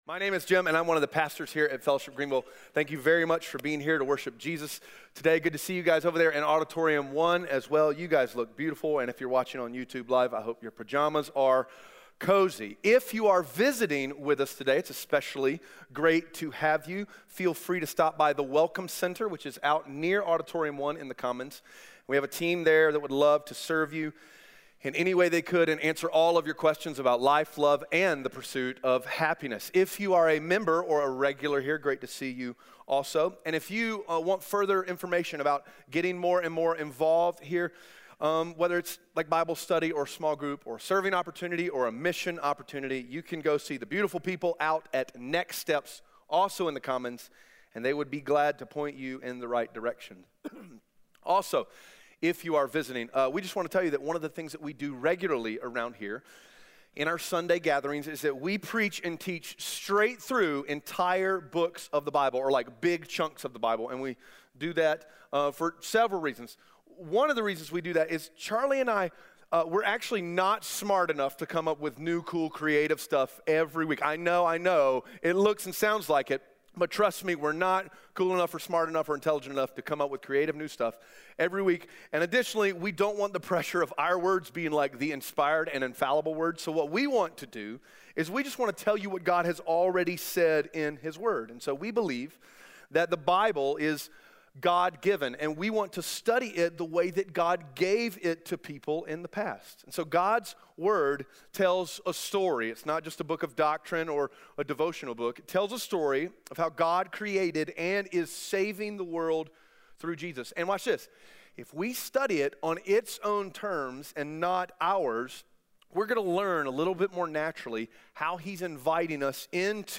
Romans 16:1-16 Audio Sermon Notes (PDF) Ask a Question Romans 16 is like the exclamation point at the end of the sentence that is Romans.